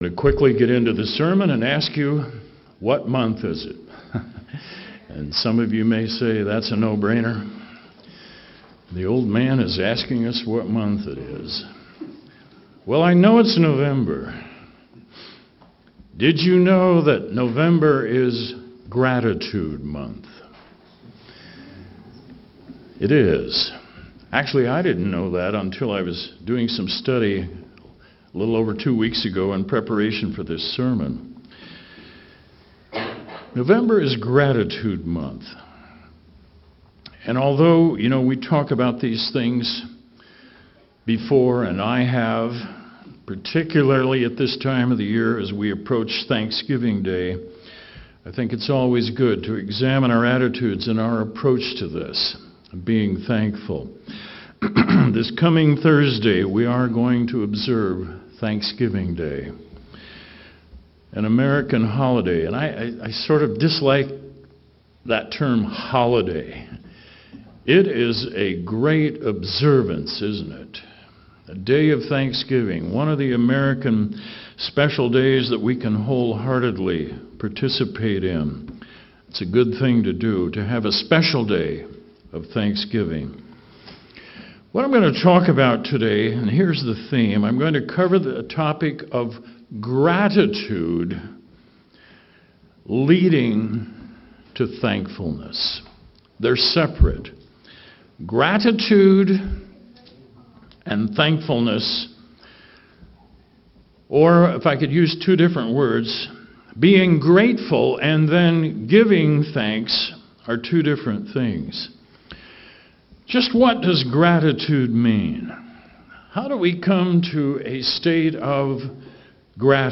This sermon explores the transformative power of gratitude, emphasizing its role in leading us to a state of true thankfulness.